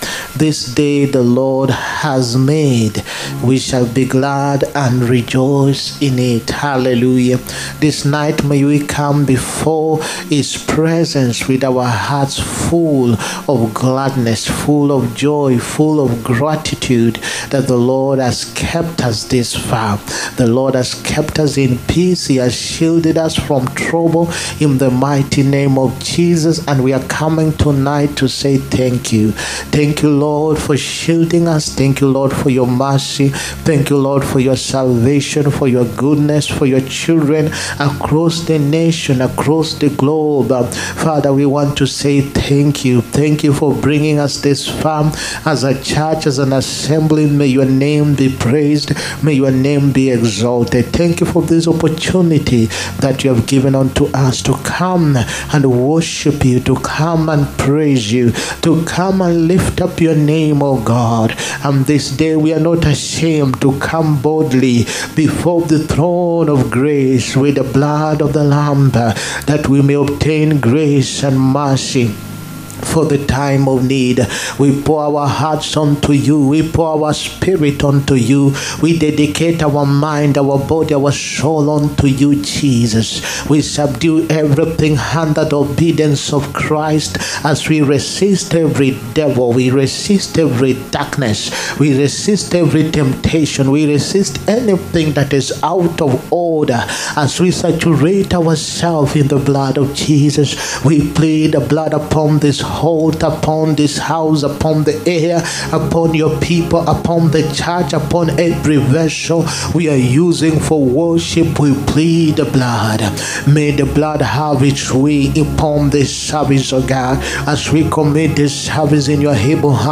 HEALING, PROPHETIC AND DELIVERANCE SERVICE. 8TH FEBRUARY 2025.